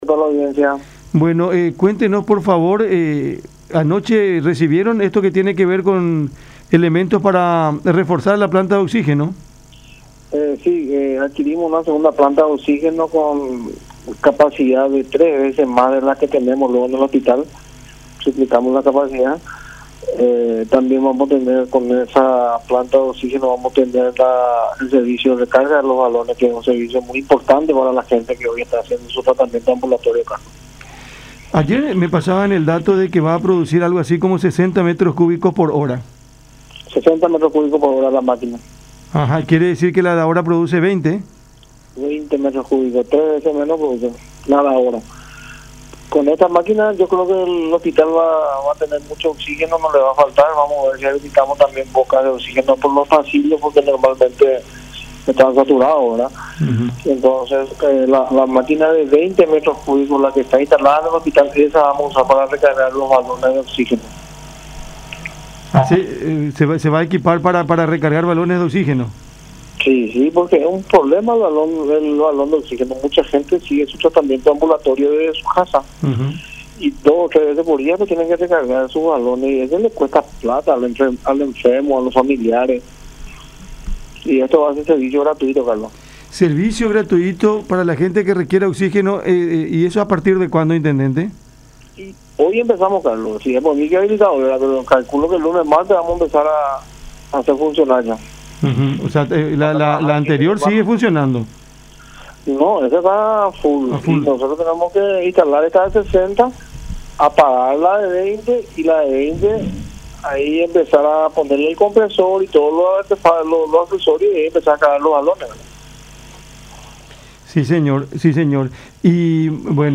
“Es para nuestra nueva planta de oxígeno. Será la segunda que tendremos en nuestro hospital distrital y tendrá la capacidad de generar hasta 60 metros cúbicos de oxígeno, el triple de lo que tenemos, porque la que tenemos tiene capacidad de 20 metros cúbicos”, explicó el intendente de Villa Elisa, Ricardo Estigarribia, en diálogo con Cada Mañana a través de La Unión.